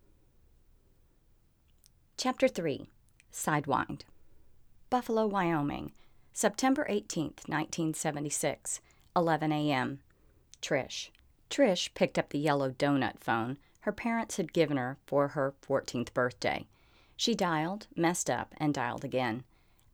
I was using a book and towel under the mic.
Given that it is POSSIBLE I switched out cables, here’s a sample that I recorded this morning, right after I recorded the room tone.
Before you get all excited about this, even at its best, that quiet room tone still has a suspicious tone in there. 120Hz (in the US) is typical of noises that lights, dimmers, some motors and some cooling fans make.